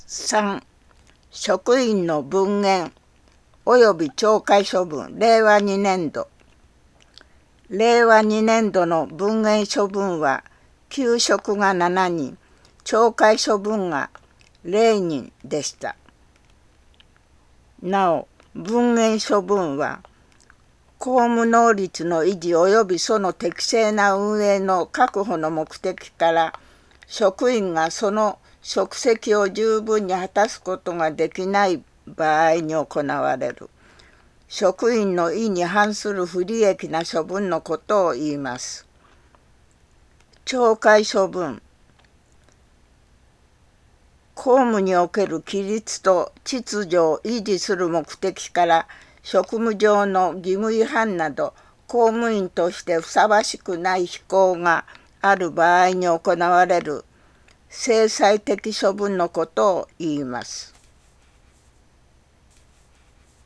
広報いみず 音訳版（令和３年10月号）｜射水市